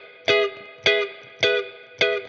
DD_StratChop_105-Emaj.wav